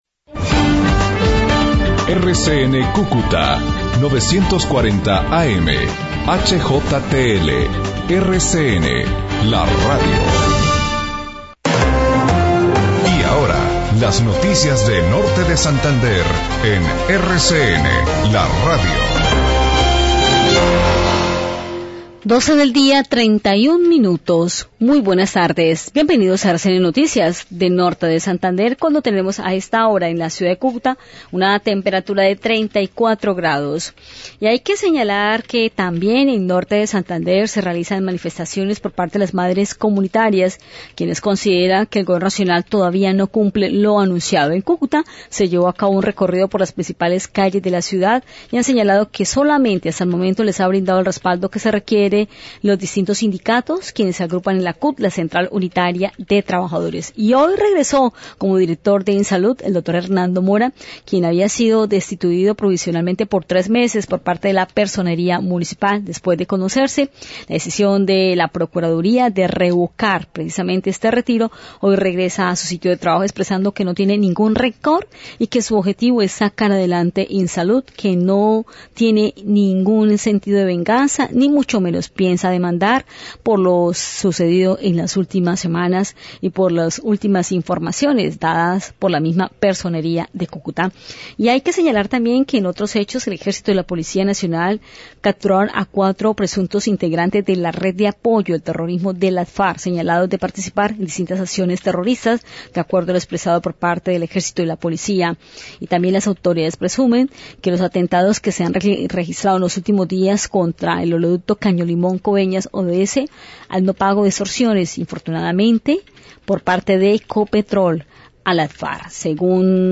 RCN 940AM Cúcuta, noticias de la mañana → Periodistas de RCN Noticias desde RCN Radio Cúcuta 940AM.